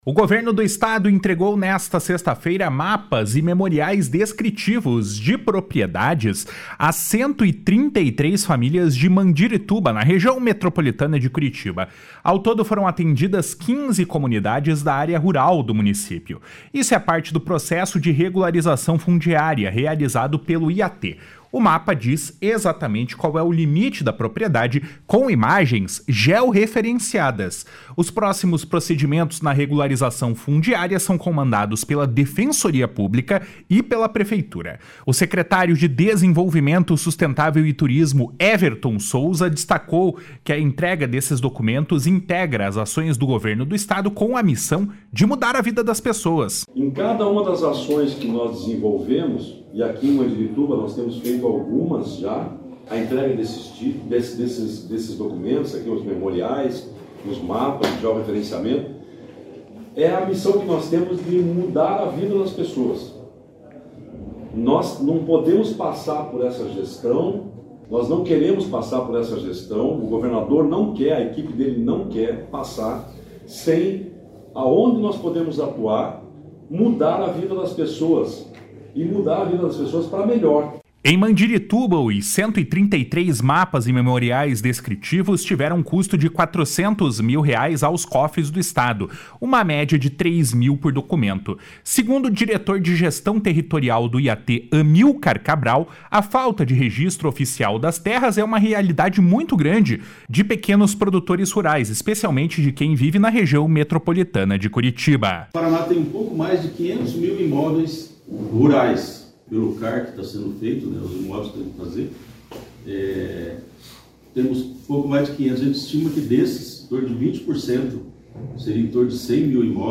O secretário de Desenvolvimento Sustentável e do Turismo, Everton Souza, destacou que a entrega desses documentos integra as ações do Governo do Estado com a missão de mudar a vida das pessoas.
O prefeito Luis Antonio Biscaia lembrou a todos os moradores que agora eles deram o primeiro passo para serem de fato donos das terras deles.